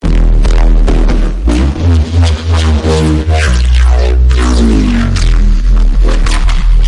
机器人节拍器2
描述：更多机器人beatboxing。 使用SFXR音效生成器创建的正弦波声音，混合在Audacity中。 无缝立体声循环，个人或商业项目无需归属。
标签： 循环 合成器 扭曲 节拍 低音 垃圾 咆哮 紧缩
声道立体声